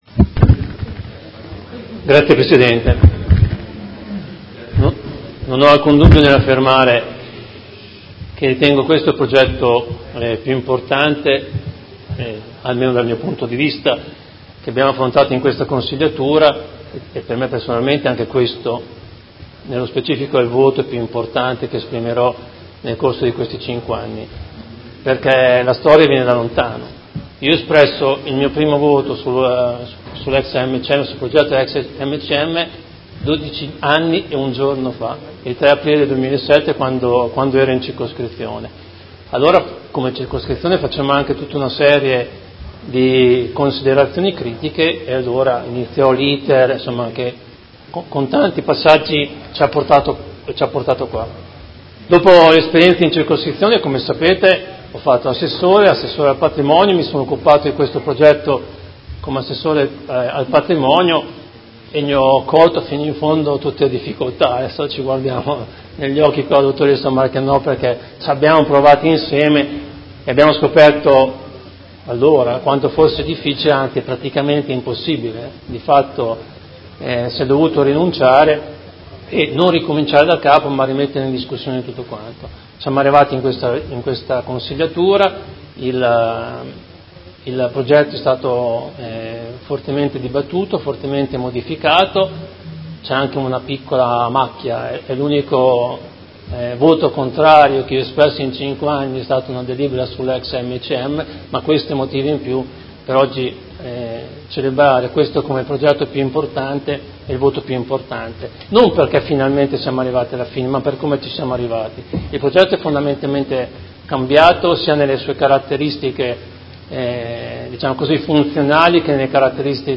Seduta del 04/04/2019 Dibattito. Delibera: Programma di riqualificazione urbana (PRU) in variante al Piano operativo comunale (POC) e al Regolamento urbanistico edilizio (RUE), all'esito della procedura competitiva con negoziazione ai sensi dell'articolo 62 del D.Lgs 18/04/2016 n. 50, per la progettazione e la esecuzione dell'intervento di riqualificazione, recupero e rigenerazione urbana del comparto EX SEDE AMCM - Parco della Creativita'.